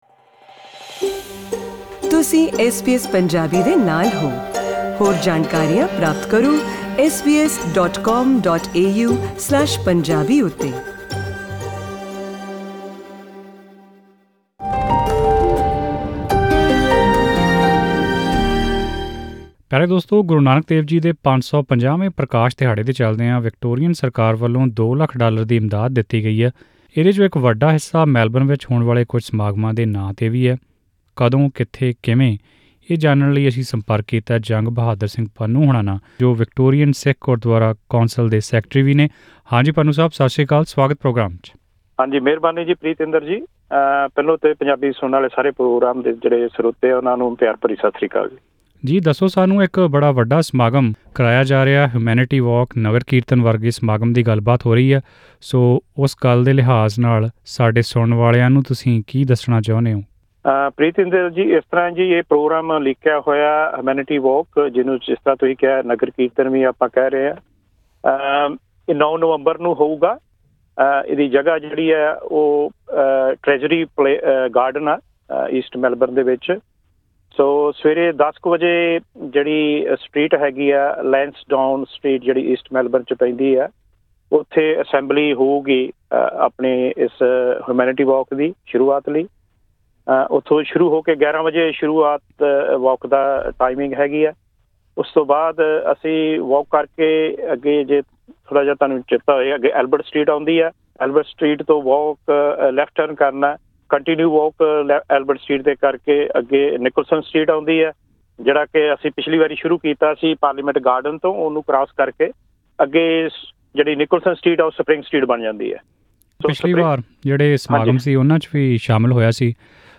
ਨਾਲ਼ ਇਹ ਵਿਸ਼ੇਸ਼ ਗੱਲਬਾਤ ਸੁਣੋ।